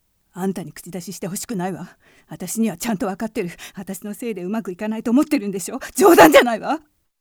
セリフ3
ボイスサンプル